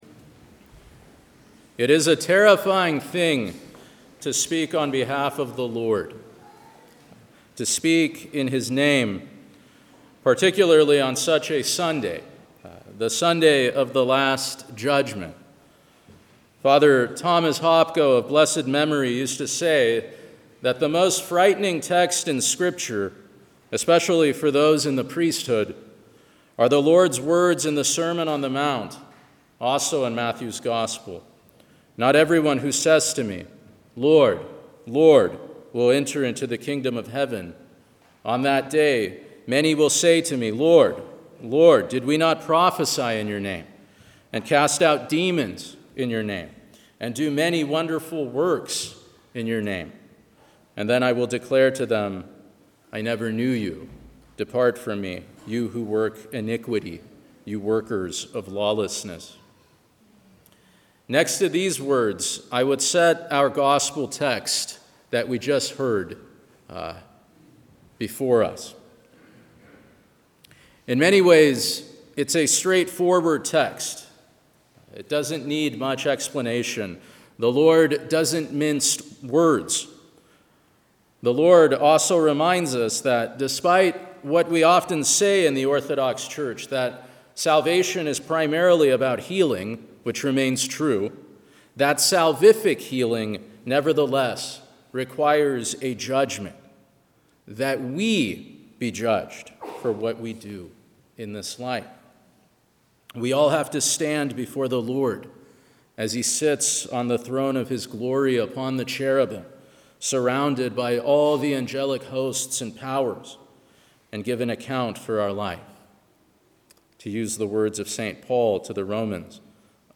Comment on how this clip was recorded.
St. Seraphim Orthodox Cathedral